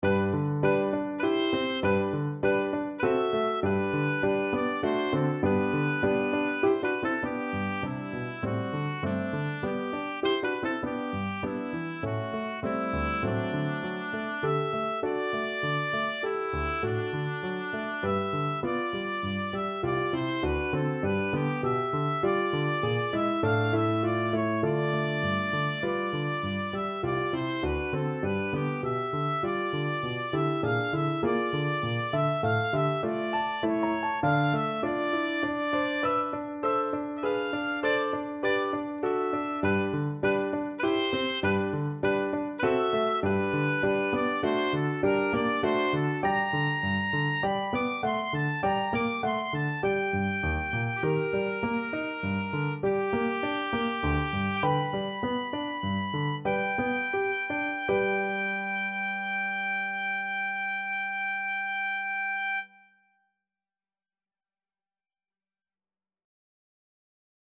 3/4 (View more 3/4 Music)
D5-D7
G major (Sounding Pitch) (View more G major Music for Oboe )
~ = 100 Allegretto grazioso (quasi Andantino) (View more music marked Andantino)
Oboe  (View more Intermediate Oboe Music)
Classical (View more Classical Oboe Music)